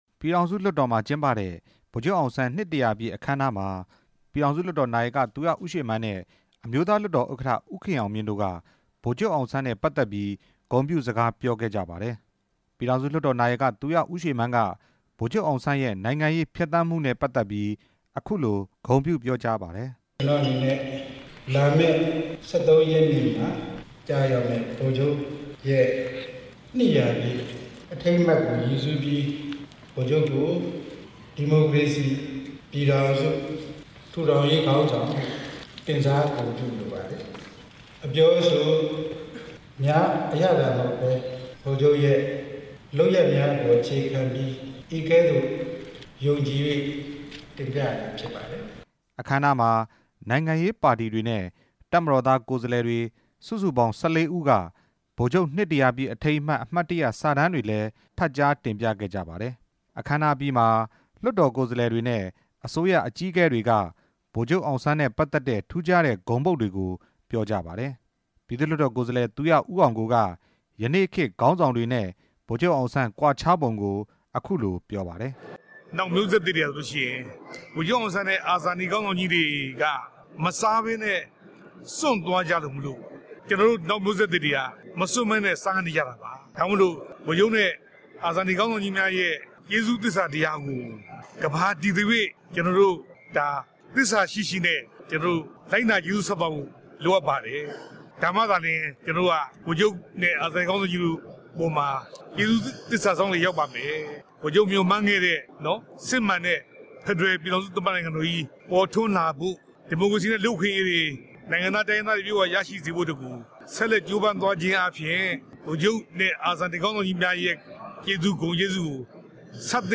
ဗိုလ်ချုပ်အောင်ဆန်း နှစ် ၁၀၀ ပြည့်မွေးနေ့အခမ်းအနား ကို နေပြည်တော် ပြည်ထောင်စု လွှတ်တော်မှာ ဒီနေ့ ကျင်းပရာ ပြည်ထောင်စုလွှတ်တော် နာယက သူရ ဦးရွှေမန်းနဲ့ အမျိုးသားလွှတ်တော် ဥက္ကဌ ဦးခင်အောင်မြင့် တို့က ဂုဏ်ပြုစကားပြောကြားပါတယ်။